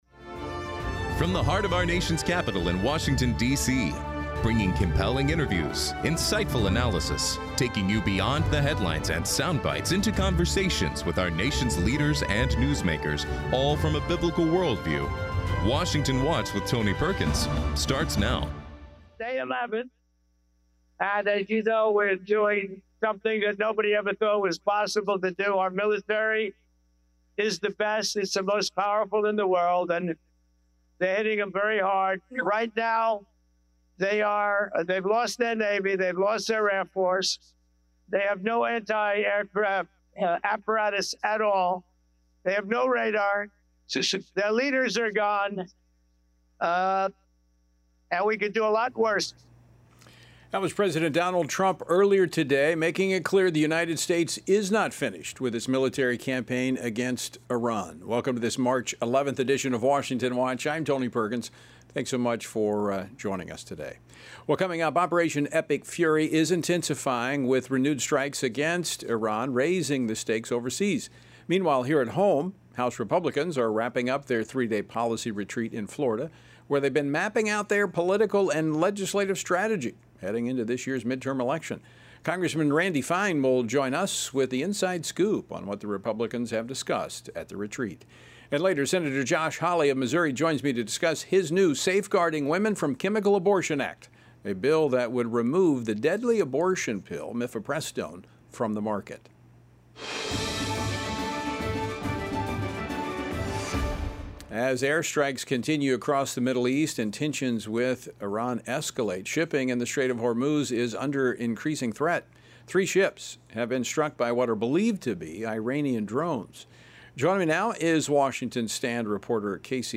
Hard hitting talk radio never has been and never will be supported by the main stream in America!